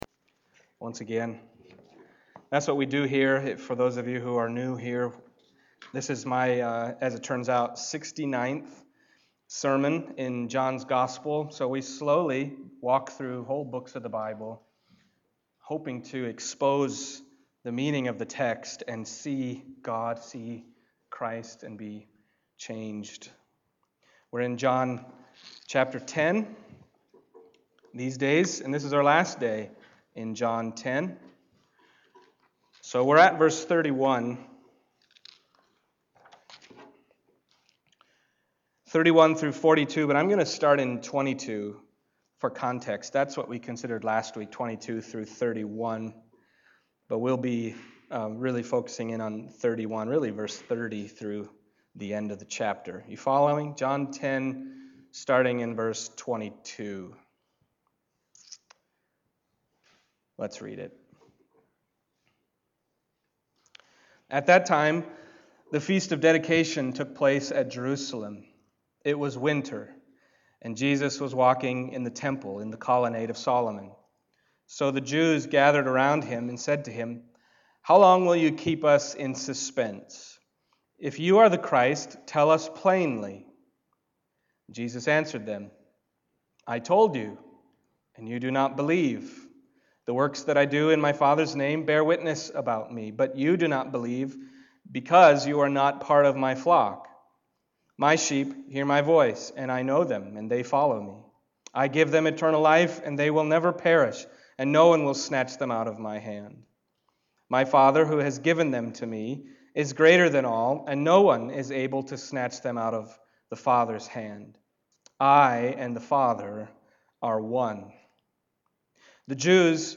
John 10:31-42 Service Type: Sunday Morning John 10:31-42 « My Sheep Will Never Perish A Resurrection …